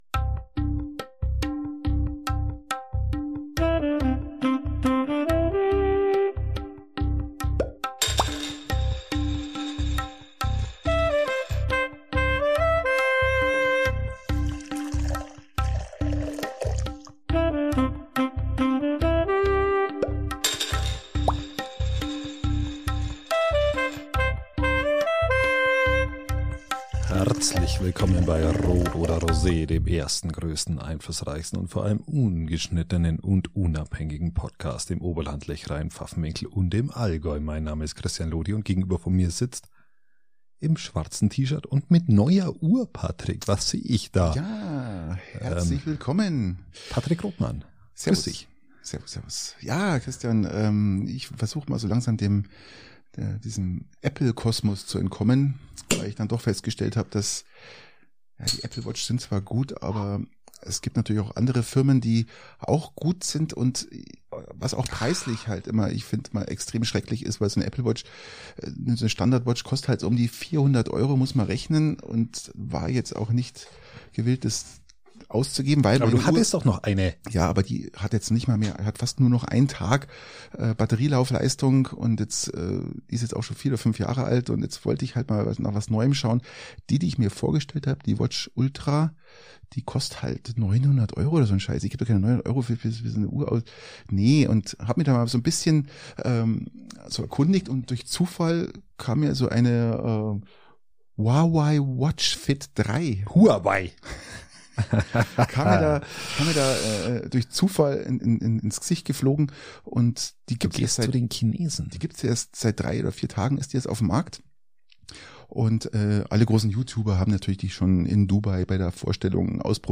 Ungeschnittenen und unabhängig!